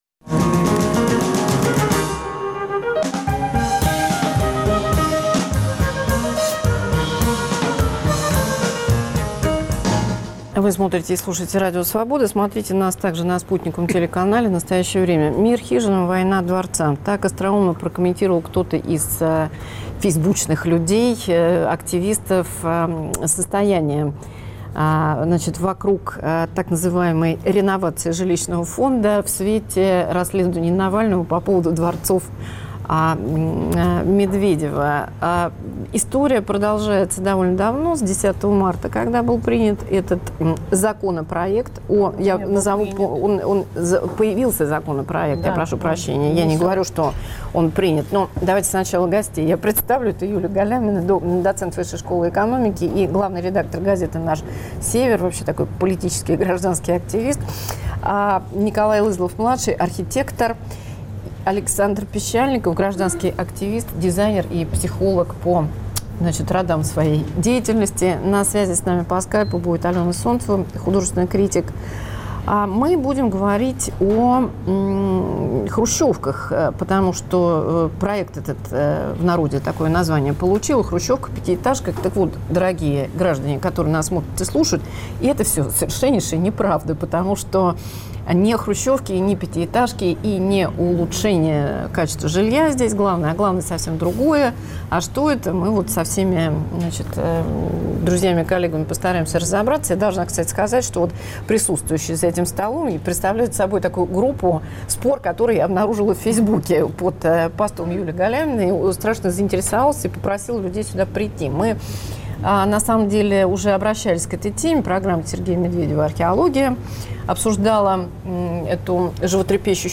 Опасности законопроекта о реновациях жилья в Москве. Как противостоять произволу бюрократов и отстоять свои квартиры? Рассуждают архитекторы и гражданские активисты.